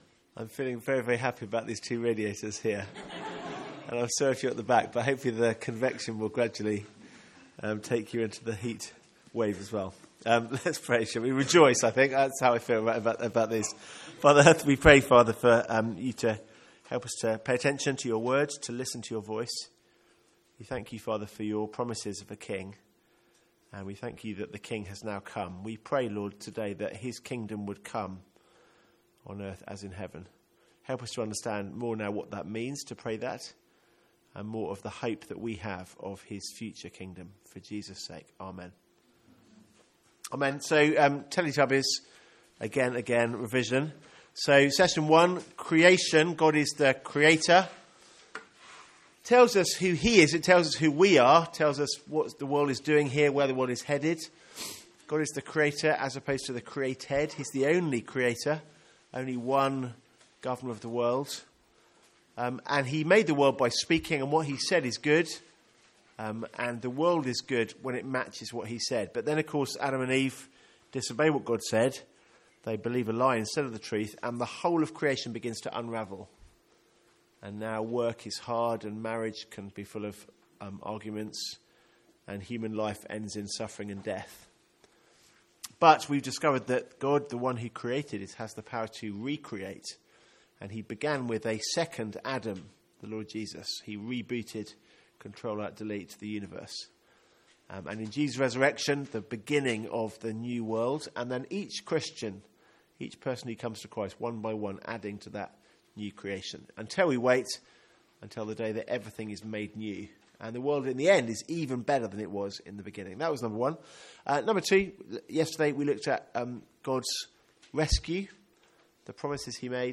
From our student Mid-Year Conference.